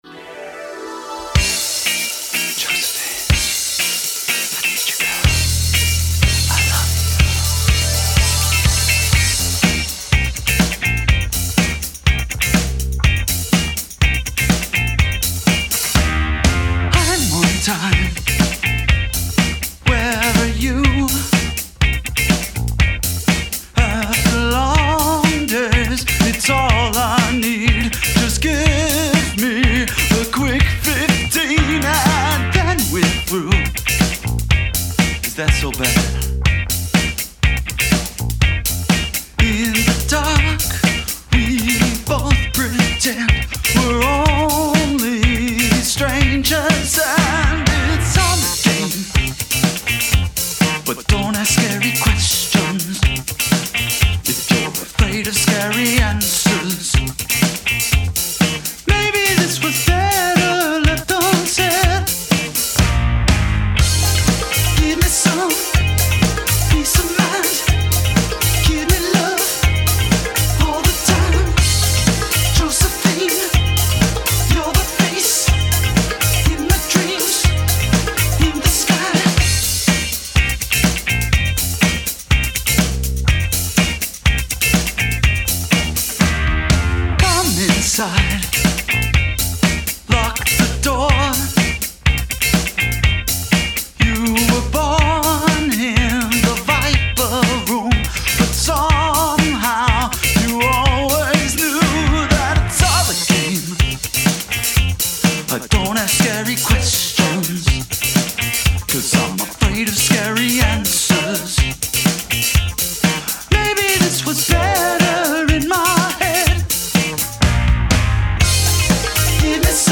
complete with scratchy rhythm guitars and a lead singer